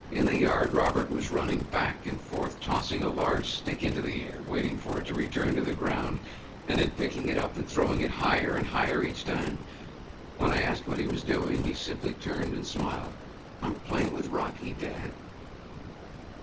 Table 2: Several examples of speech projected onto subsets of cepstral coefficients, with varying levels of noise added in the orthogonal dimensions.